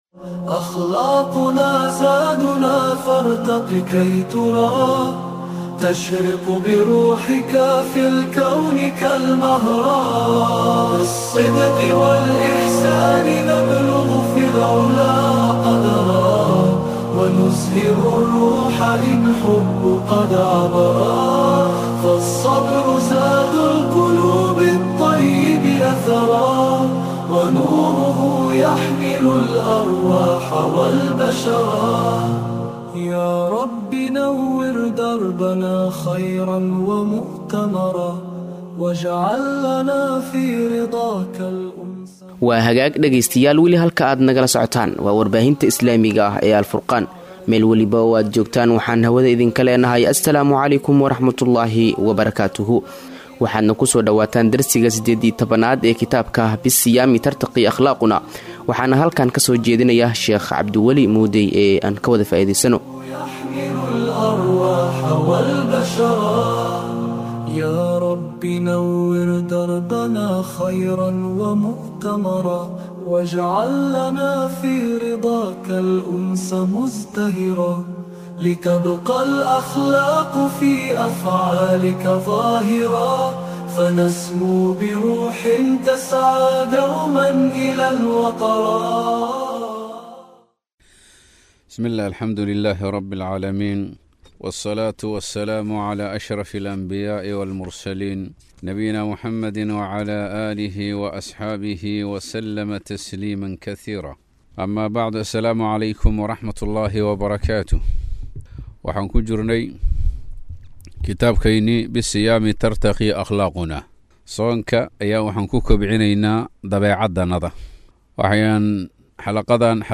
Inta lagu guda jiro bishaan waxaan dhageystayaasheenna ugu tala galnay duruus Ramadaani ah